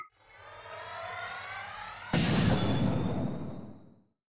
File:Psychic insanity lance charge fire.ogg
Psychic insanity lance charge up and fire sound effects